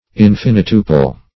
Search Result for " infinituple" : The Collaborative International Dictionary of English v.0.48: Infinituple \In*fin"i*tu`ple\, a. [Cf. Quadruple .] Multiplied an infinite number of times.